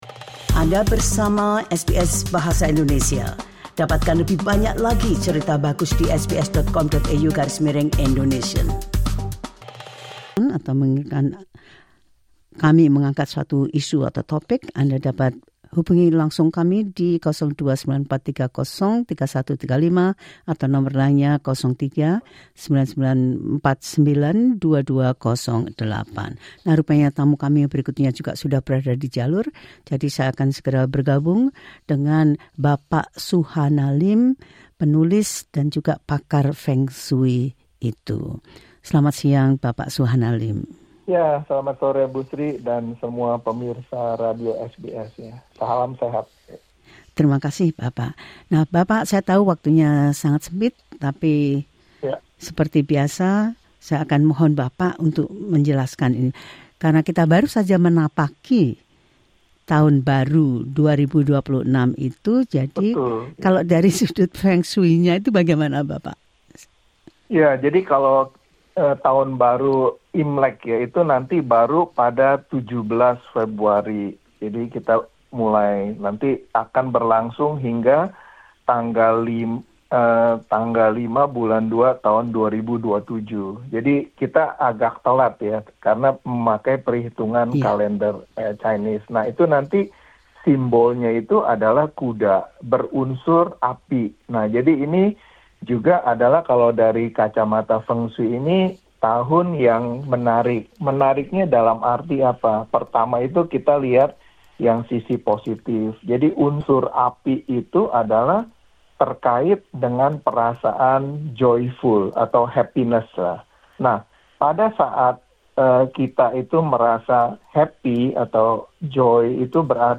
Dalam wawancara di Radio SBS